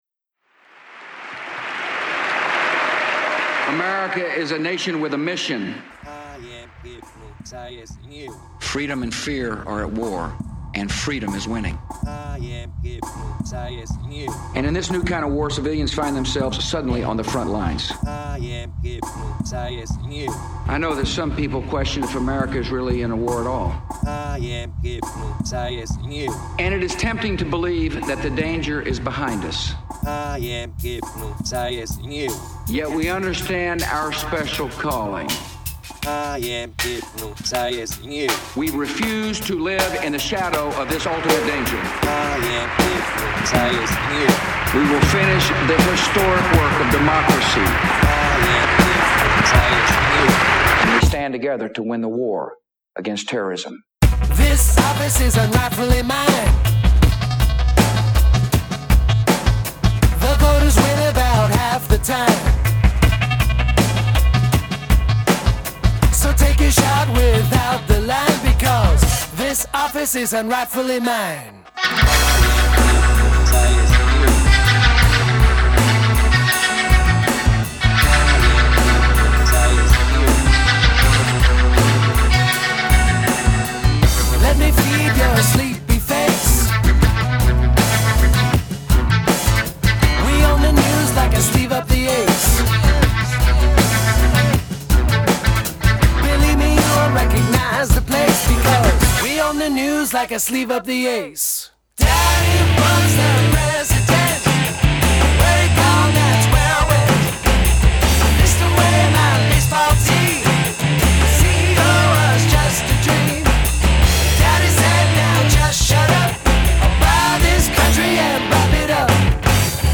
Drums are from SampleTank, programmed by me. Guitar sounds are from POD (sue me). Bushie speak is constructed from public domain samples.
Hey-- with a track laying down THAT funky of a groove-- turn up the bass guitar!!
Great vocals, btw!
This is very well mixed.
Those backround vocals kickasss.